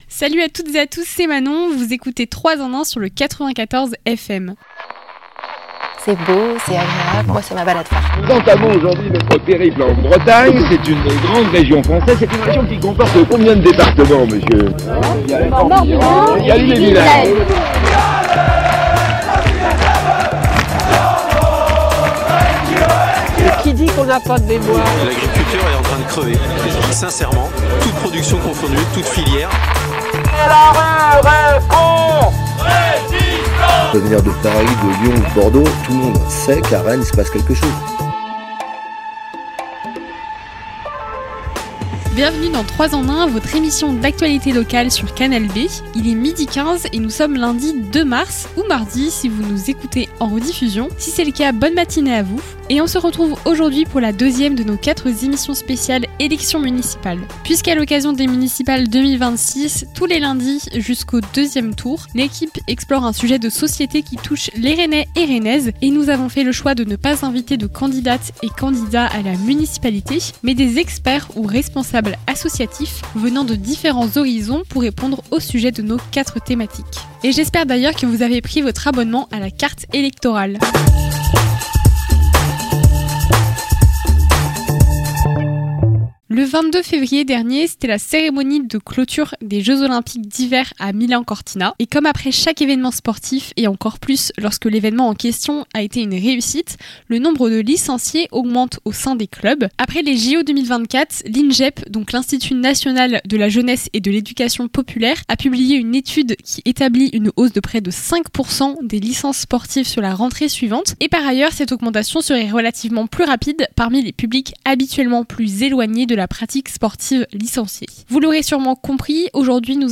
À travers des micro-trottoirs et interventions de spécialistes, l'objectif est de comprendre les enjeux de ces problématiques et du rôle de la mairie dans leurs potentielles évolutions.